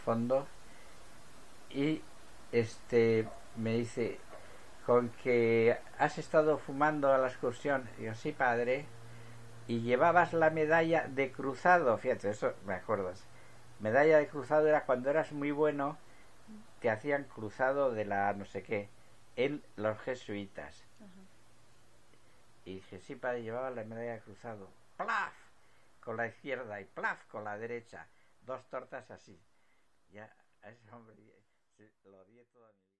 Interviews with women and men who lived the first years of the Franco regime.